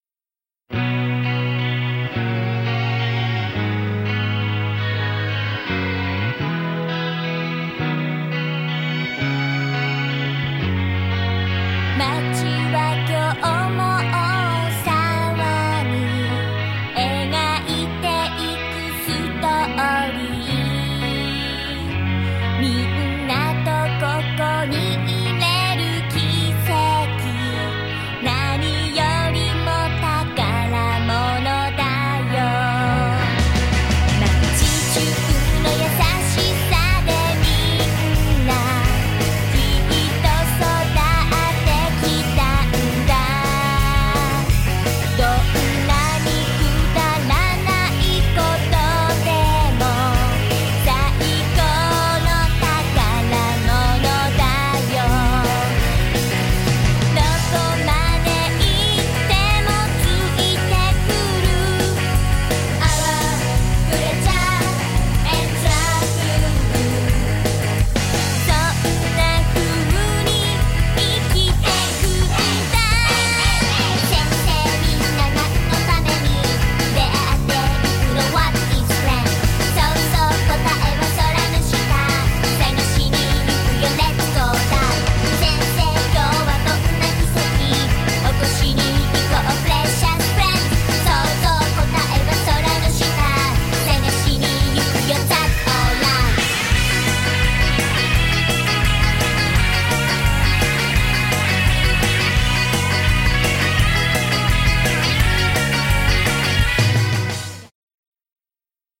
reduced by -6dB